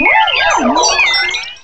cry_not_shiinotic.aif